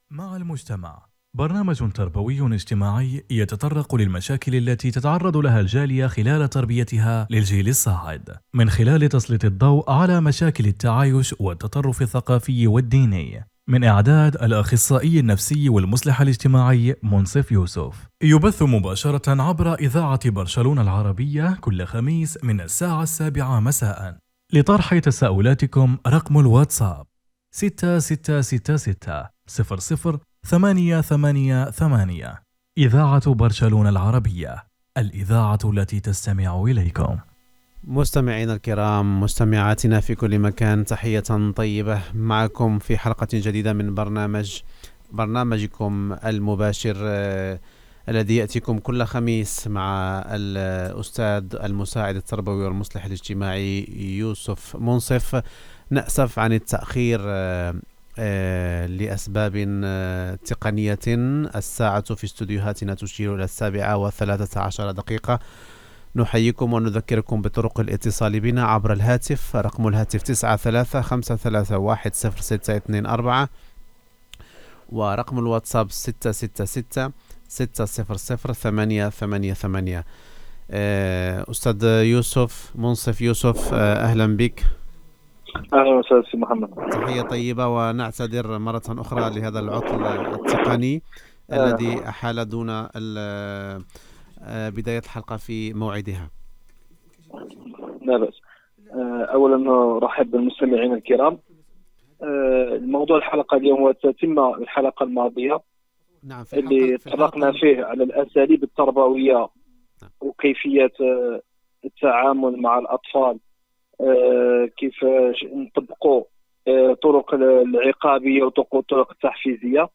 Identificació, telèfons de contacte, presentació i entrevista
Entreteniment